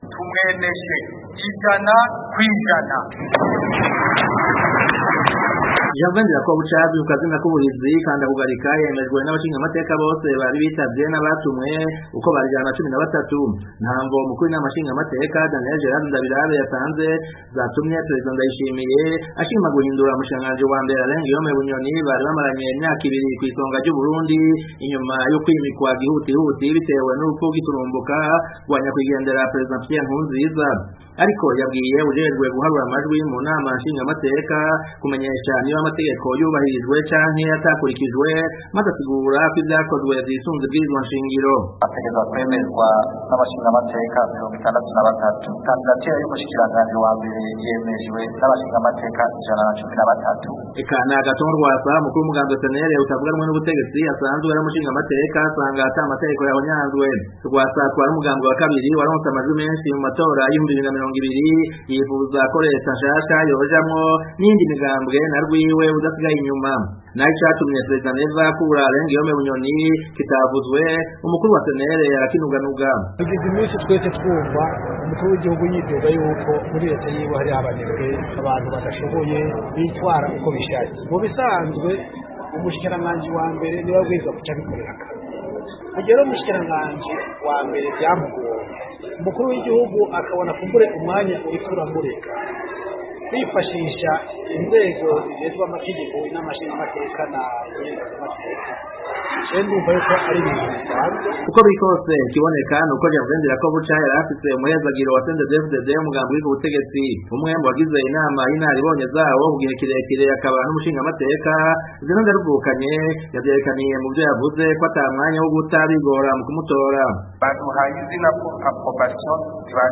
Ibirori vyo Kurahiza Ndirakobuca